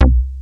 HARD C3.wav